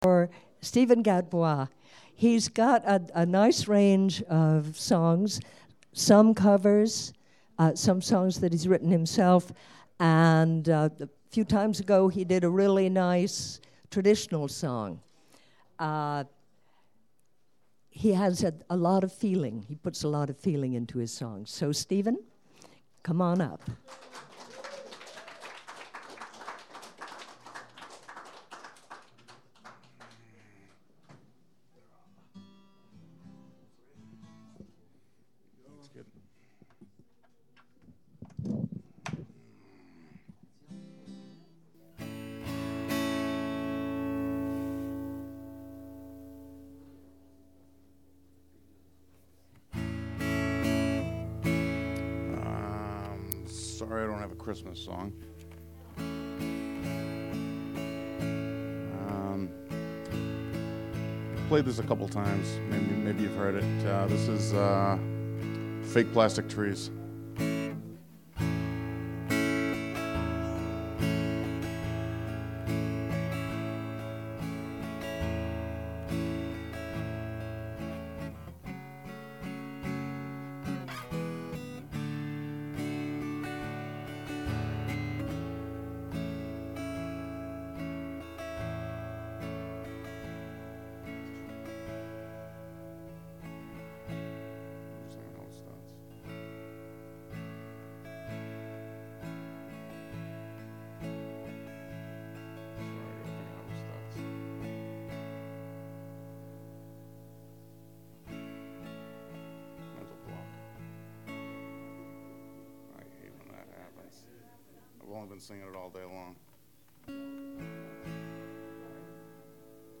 [Knowledge Domain Interpretation]   Savoury Lane Open Mike Private Files
Files with a title starting raw or Untitled have only been track-level volume adjusted and are not joined, clipped, equalized nor edited.